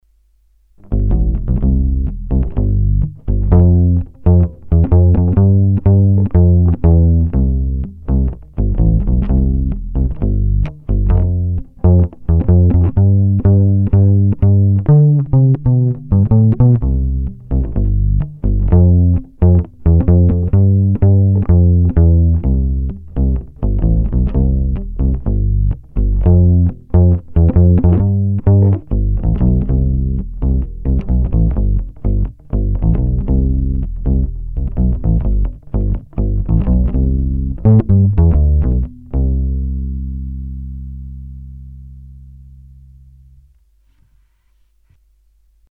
Už kdysi dávno u zmíněného nástroje Ashbory vyzdvihovali v ukázkách charakteristický kontrabasový zvuk, a ten je i zde.
Basové ukulele bylo zapojeno rovnou do vstupu zvukové karty a jinak zvuk jen normalizován, ponechán bez postprocesingu. Basy na aktivní elektronice jsem nechal naplno, ale výšky jsem úplně stáhnul abych omezil pazvuky dané piezo snímačem.
Ukázka "nasucho"
Myslím, že tam kontrabasový charakter výrazně zaznívá.